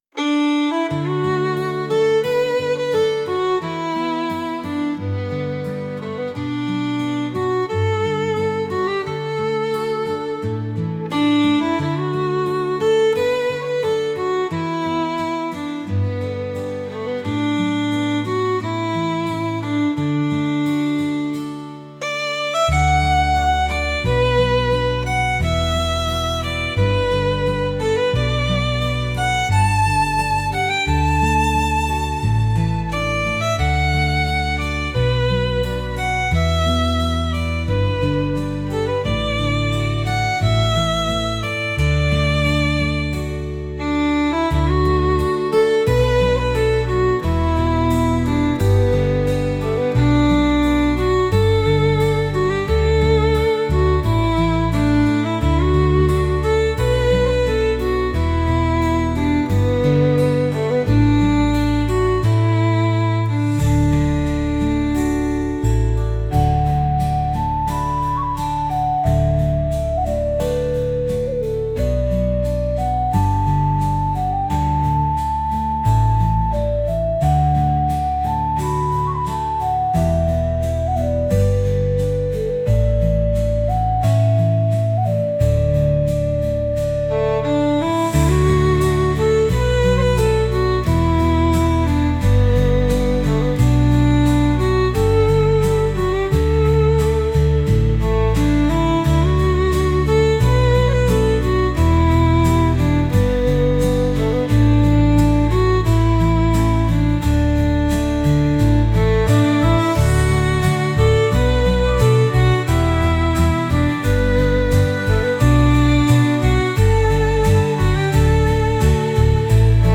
穏やかな水面を象徴するようなケルト音楽です。